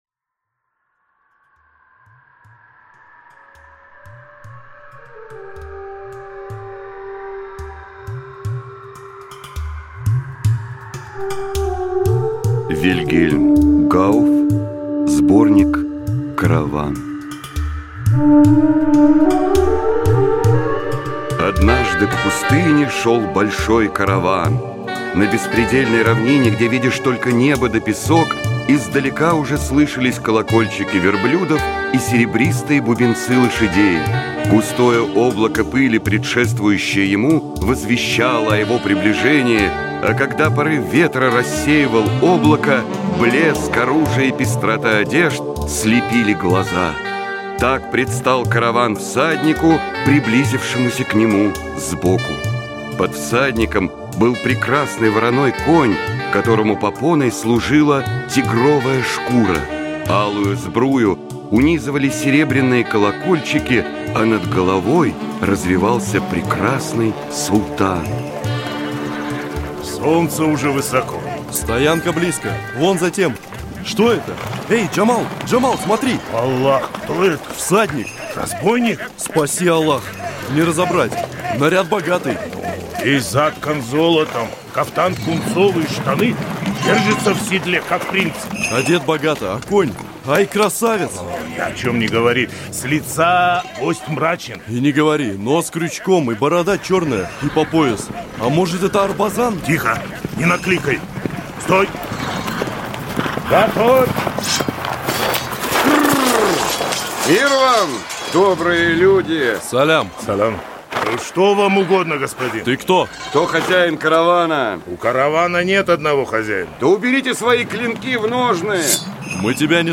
Аудиокнига Караван (7 сказок в спектаклях) | Библиотека аудиокниг